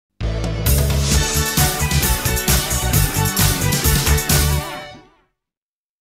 Example of an ad